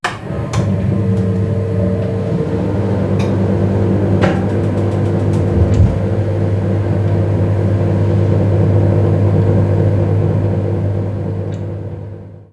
Encendido de caldera de gas
caldera
Sonidos: Hogar